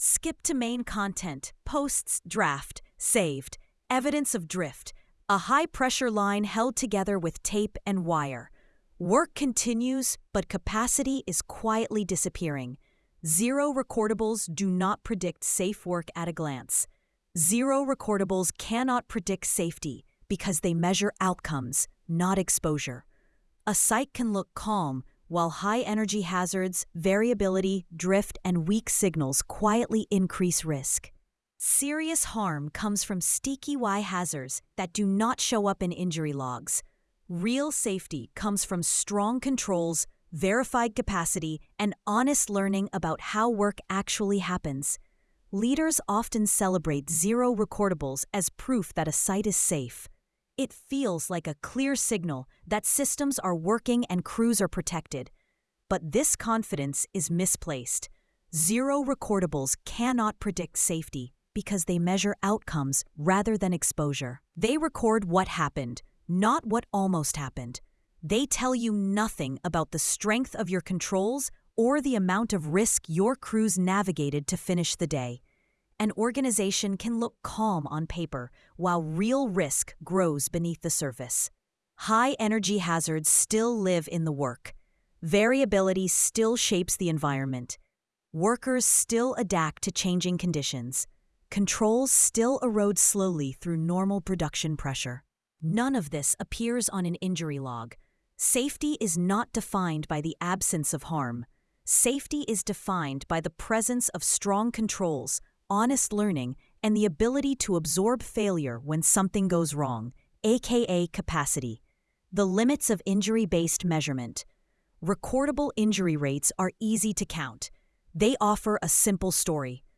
sage_gpt-4o-mini-tts_1x_2025-12-03T05_57_51-456Z.wav